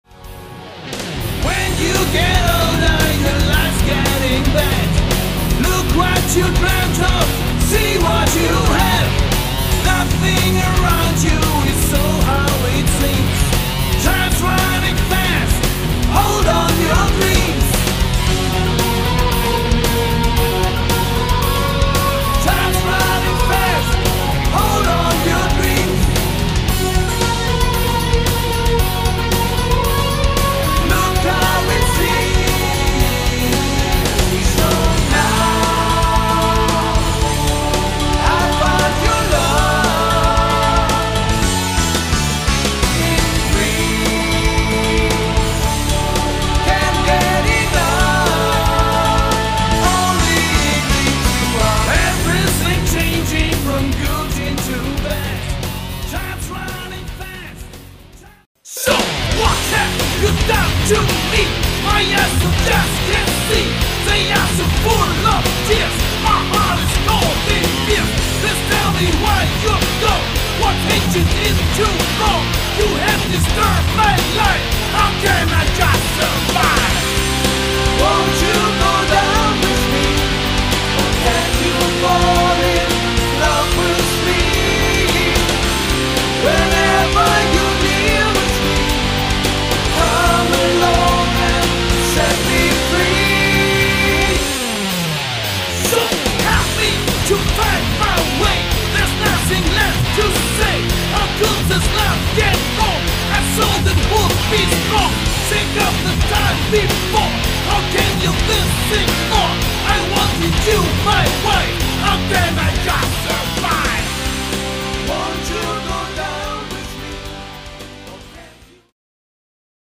druckvolle Arragements, eingängige Melodien
Gitarre
Schlagzeug
Keyboards
Bass
Lead Vocals